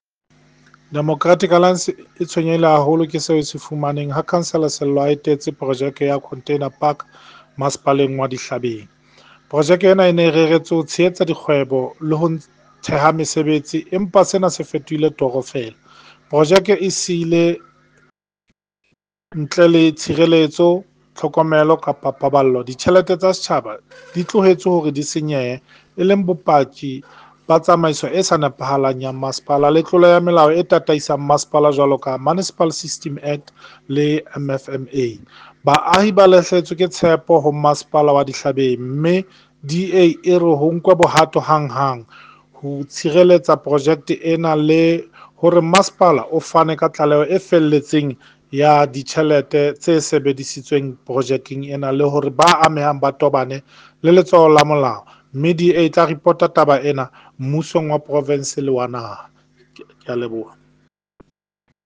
Sesotho soundbites by Cllr Sello Makoena